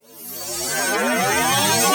VEC3 Reverse FX
VEC3 FX Reverse 30.wav